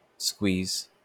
IPA/skwiːz/
wymowa amerykańska?/i